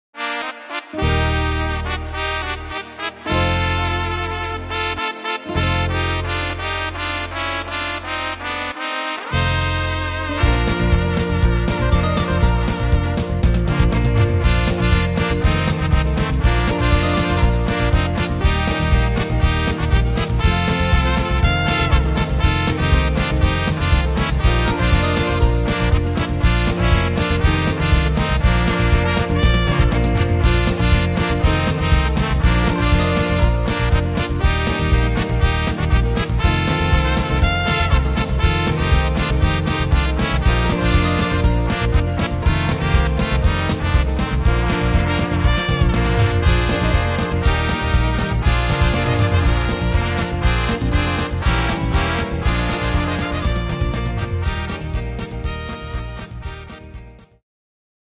Tempo: 210 / Tonart: Bb-Dur